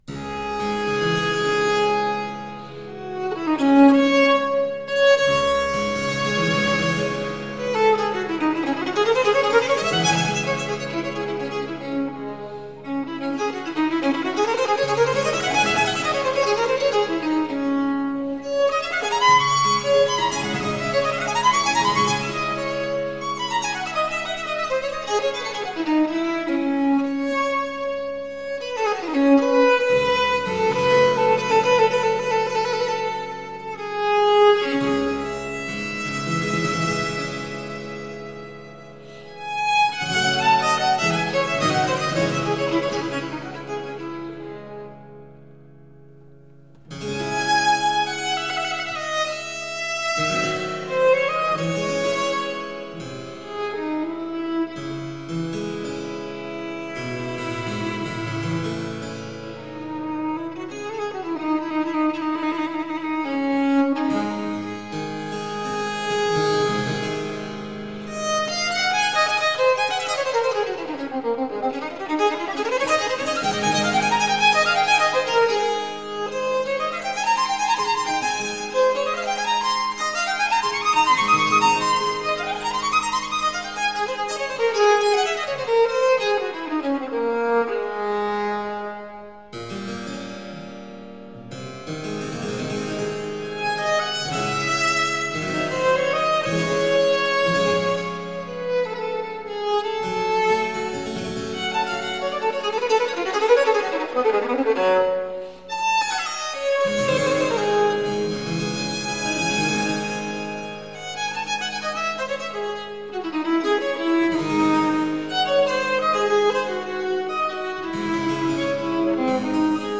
Baroque trio.
Classical, Chamber Music, Baroque, Instrumental
Harpsichord, Viola da Gamba, Violin